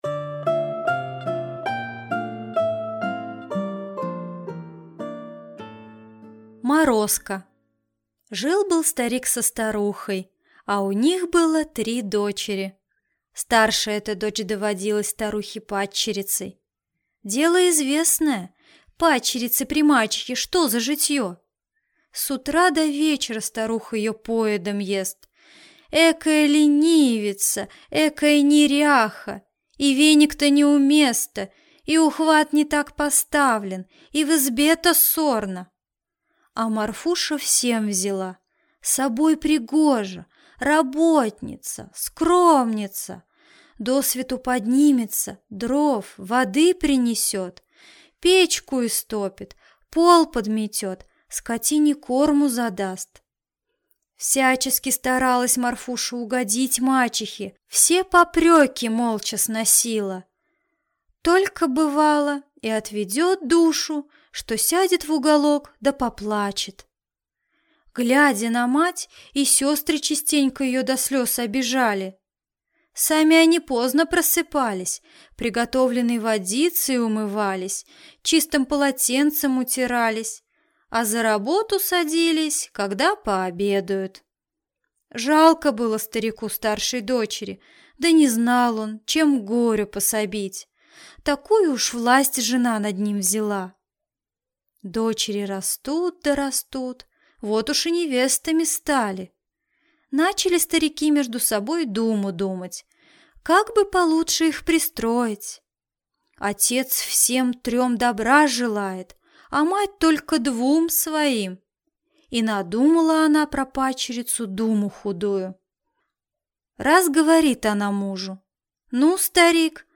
Аудиокнига Морозко | Библиотека аудиокниг